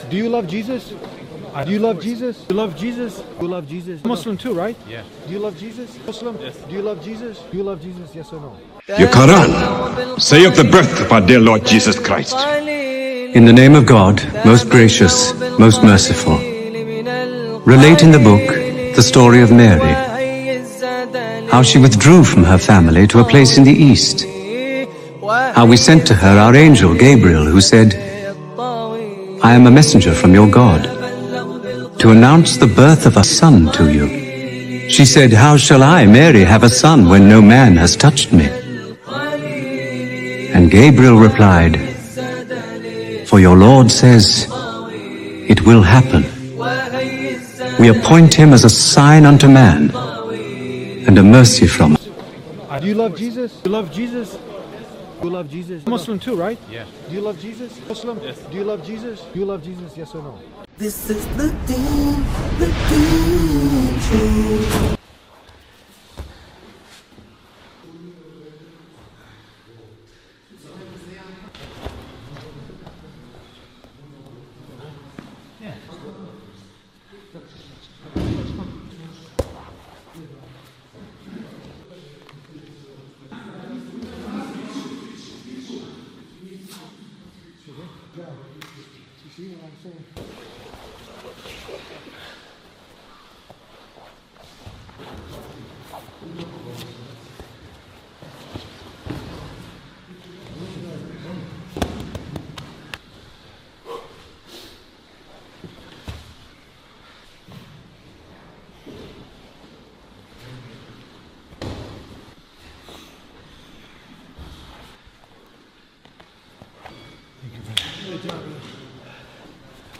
The street interviews in this video reveal a truth that shatters the Islamophobic narrative: every single Muslim asked about Jesus confirms their deep love and reverence for him. One Muslim points out that if you take the J off “Jesus,” you get “Esus,” which is closer to his actual Aramaic name Isa.